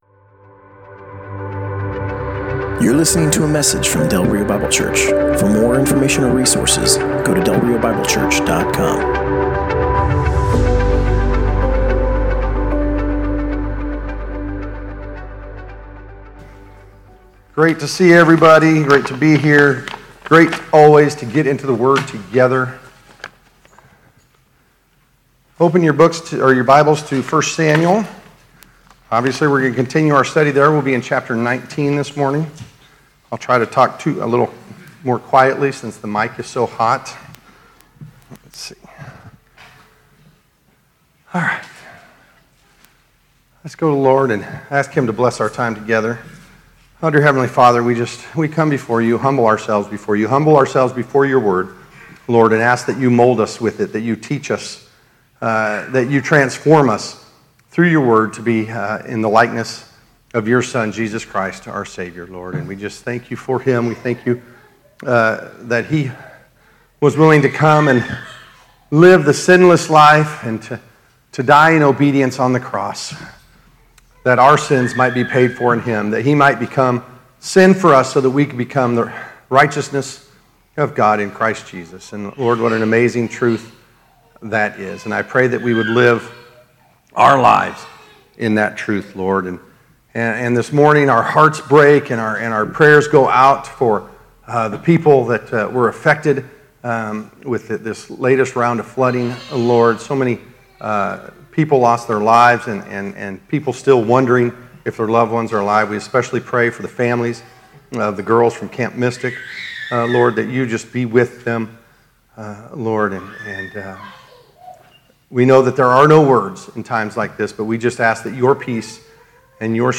Passage: 1 Samuel 19: 1-24 Service Type: Sunday Morning https